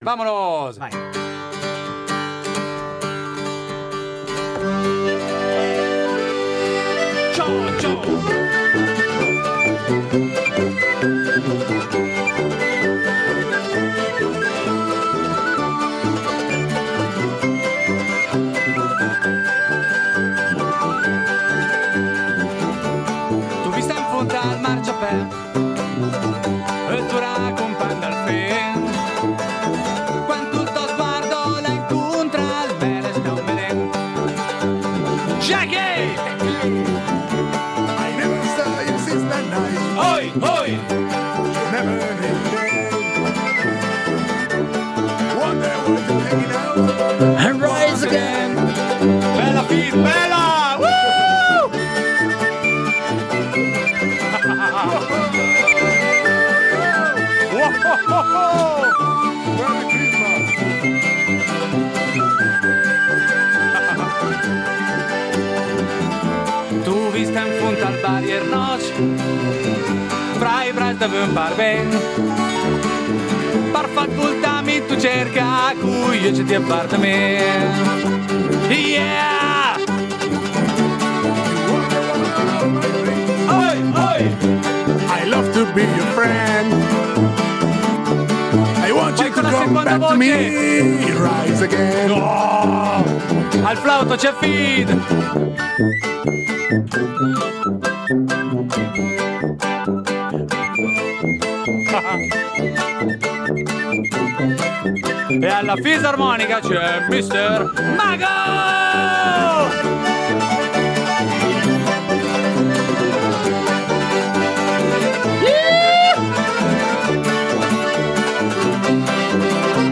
L'assolo di "bocca"